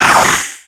Cri de Mangriff dans Pokémon X et Y.